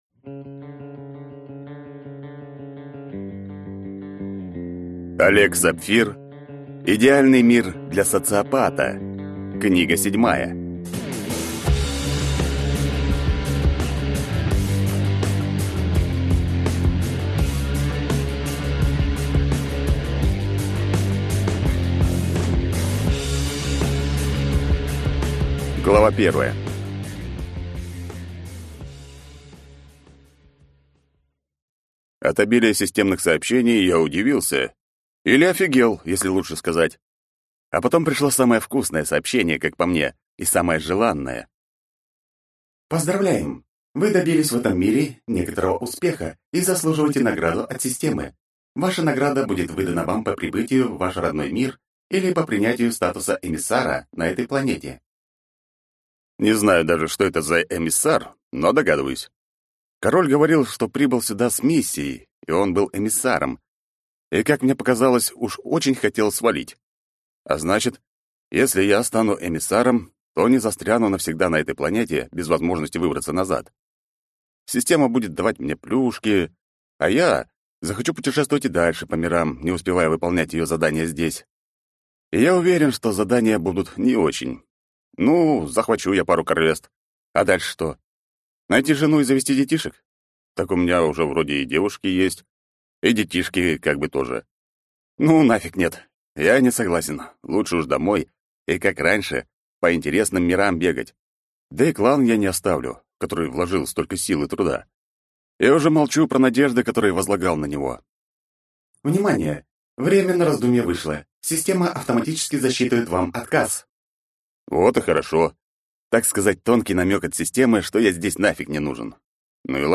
Идеальный мир для Социопата 7 (слушать аудиокнигу бесплатно) - автор Олег Сапфир